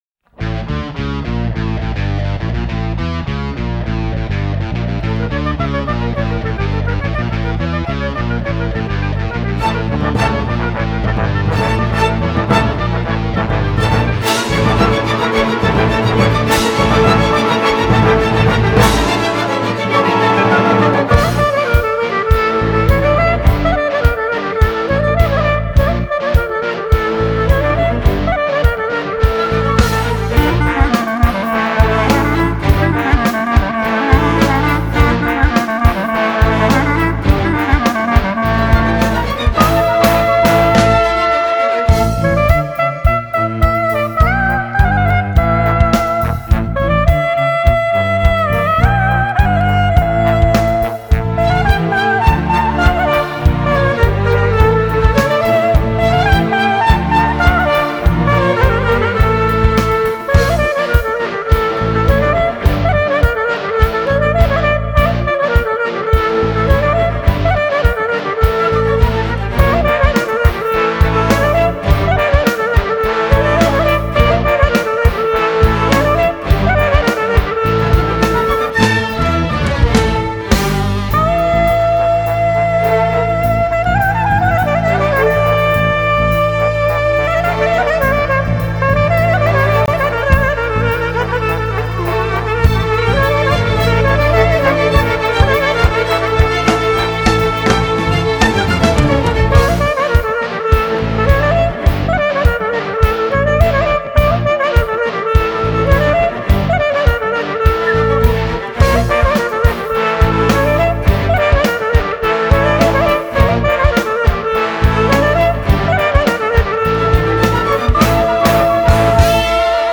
Genre: Balkan Folk, World Music